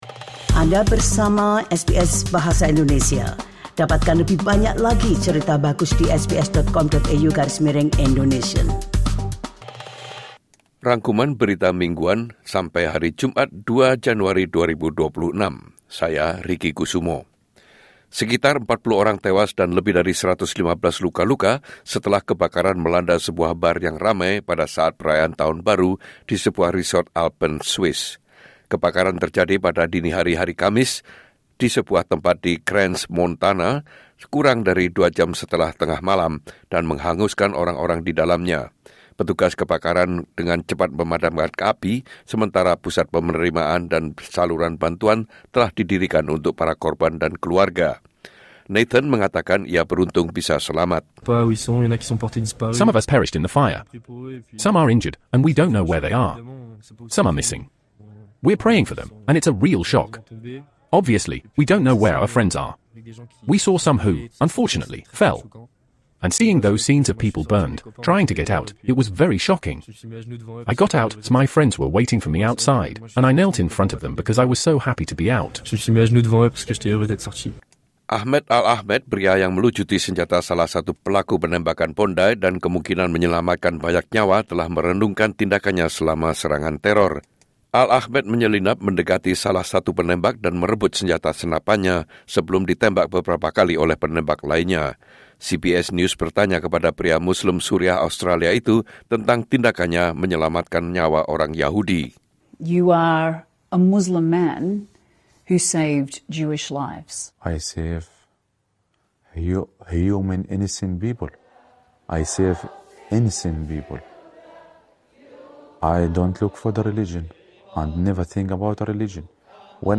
Rangkuman Berita Mingguan SBS Audio Program Bahasa Indonesia - Jumat 2 Januari 2026